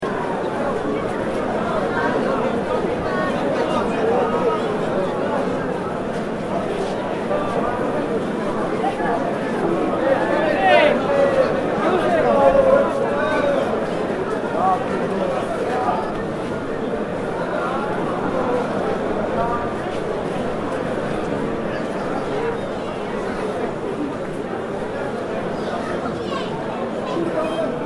جلوه های صوتی
دانلود صدای پیاده روی در سالن از ساعد نیوز با لینک مستقیم و کیفیت بالا
برچسب: دانلود آهنگ های افکت صوتی طبیعت و محیط دانلود آلبوم صدای محیط از افکت صوتی طبیعت و محیط